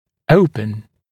[‘əupən][‘оупэн]открывать, открытый